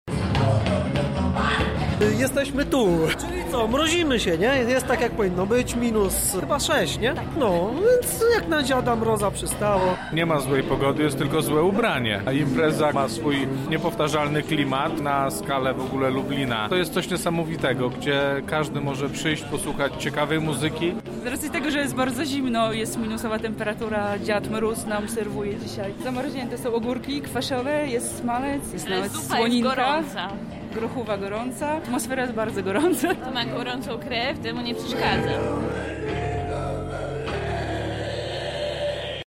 Mroźne spotkanie z niestandardowymi dźwiękami i sztuką alternatywną.
W pustostanie „Opokan Zona” przy – 6°C miłośnicy awangardowych brzmień zgromadzili się by świętować dzień Dziadka Mroza.
Relację